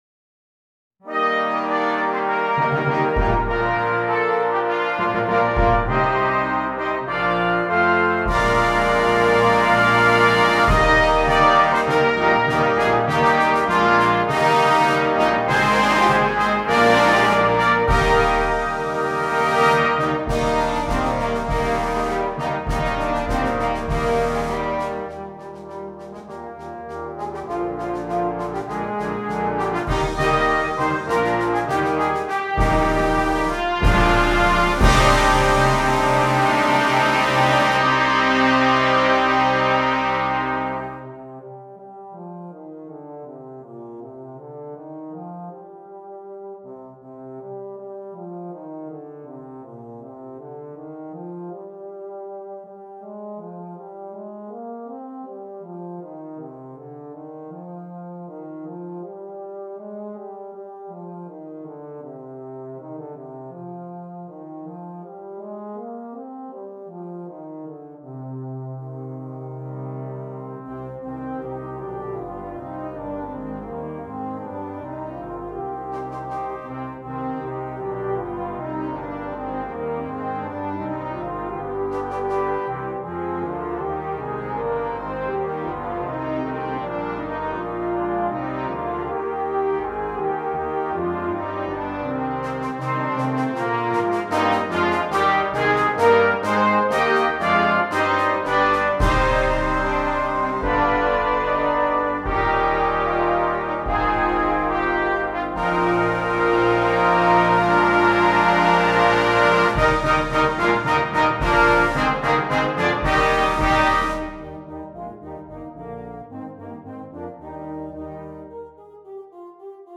Brass Choir
Traditional